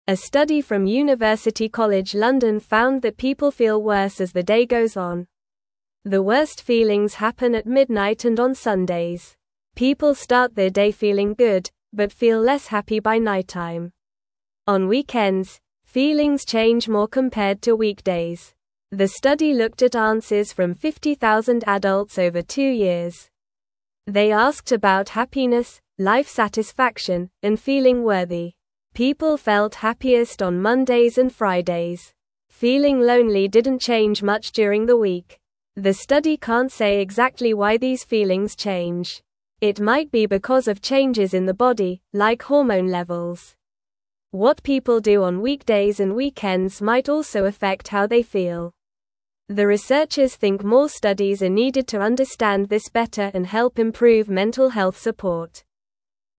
Normal
English-Newsroom-Lower-Intermediate-NORMAL-Reading-Why-People-Feel-Happier-at-the-Start-of-Days.mp3